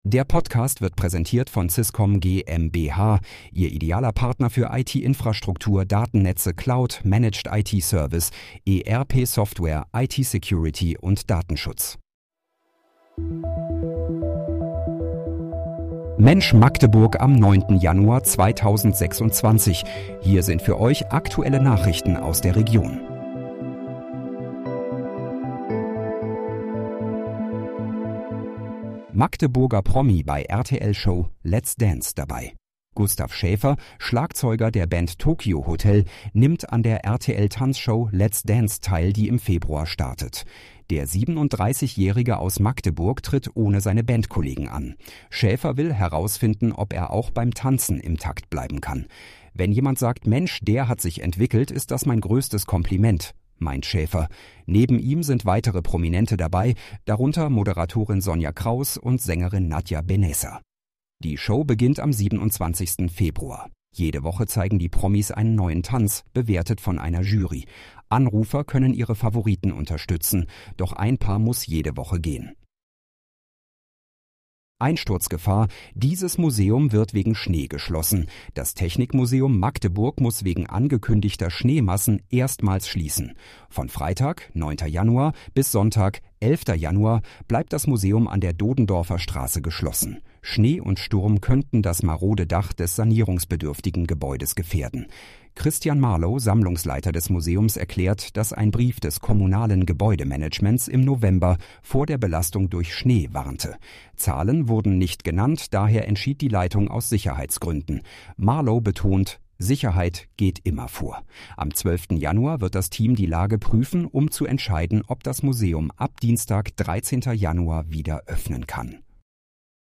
Mensch, Magdeburg: Aktuelle Nachrichten vom 09.01.2026, erstellt mit KI-Unterstützung